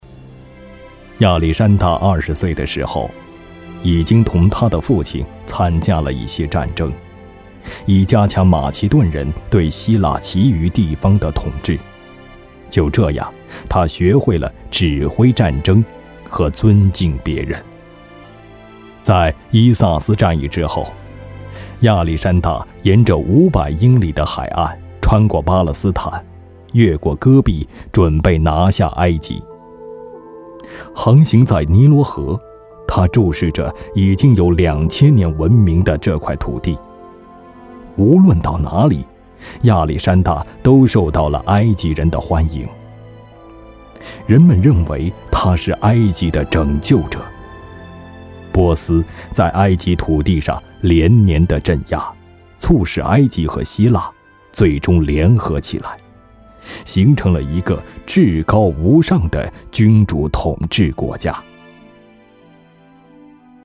大气浑厚 人物专题
大气浑厚男中音，厚重激情。擅长激情宣传片，记录片，工程解说，政府专题等。作品：郑州火车站解说。